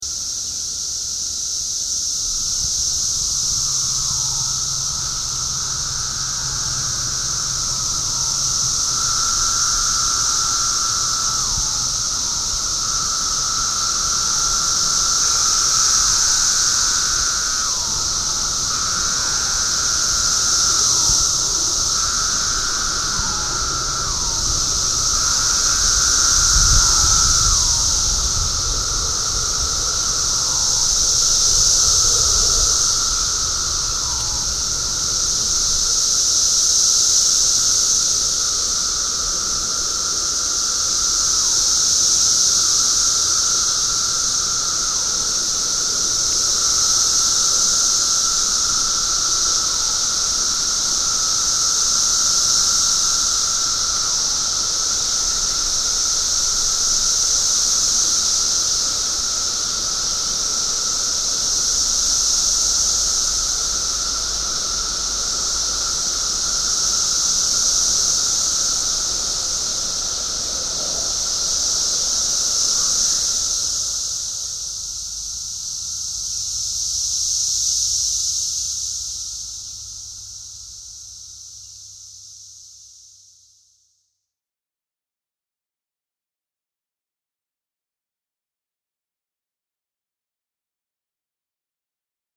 Un souvenirs du Sud des Vacances!
19/03/2008 LEs cigales des vacances !